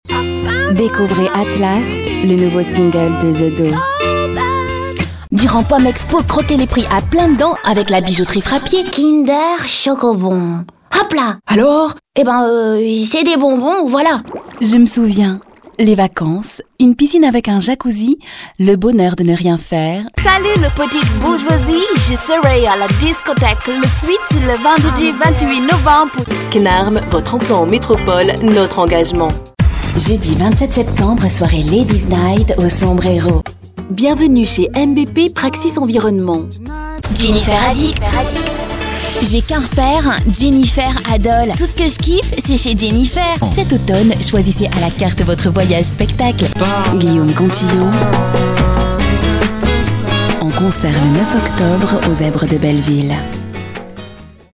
Mon timbre est dans les aigus léger, un ton naturel ou posé, une voix jeune, douce ou tonique, sensuelle ou journalistique, sérieuse ou séductrice, avec différents accents ou encore de cartoons!
Sprechprobe: Industrie (Muttersprache):